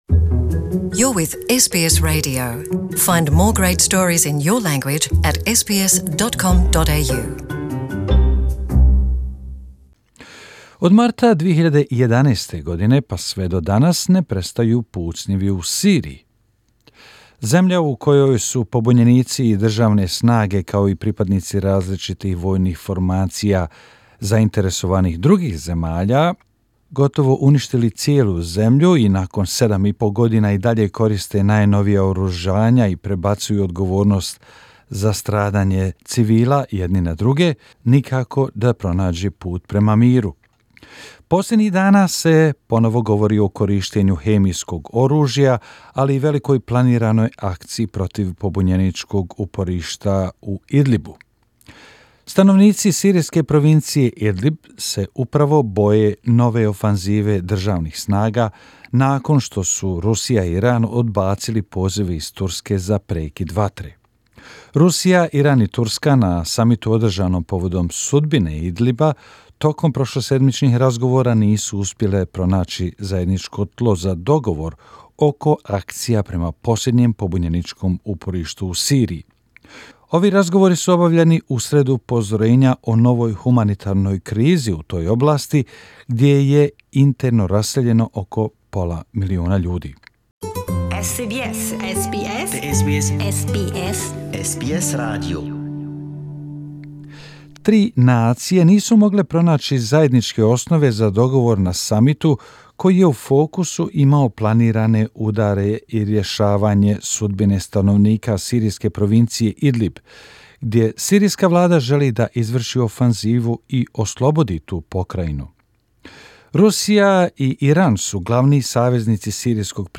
Residents of the Syrian province of Idlib ((id-lib)) are fearful of a new government offensive, after Russia and Iran rejected calls from Turkey for a ceasefire. Russia, Iran and Turkey were unable to find common ground at a summit on the fate of Idlib, which is Syria's last rebel stronghold. It comes amid warnings over a looming humanitarian crisis in the area, where one and a half million people are internally displaced.